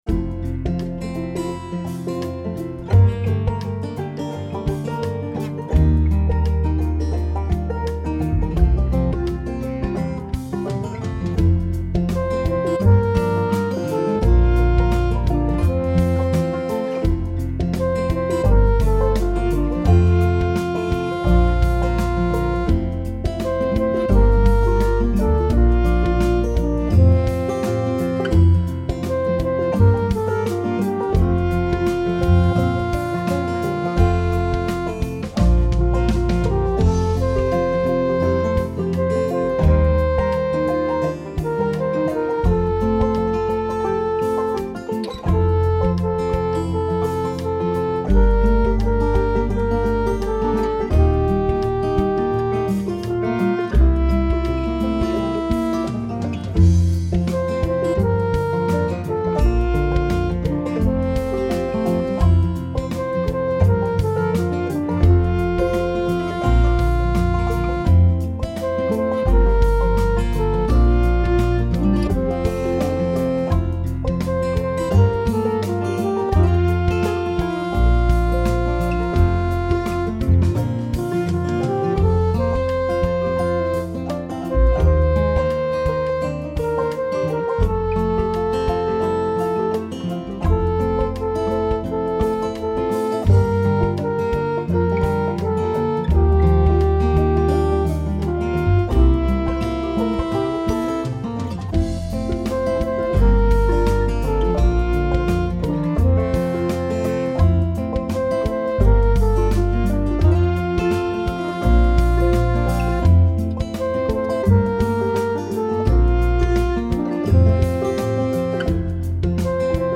This is a song for Eucharist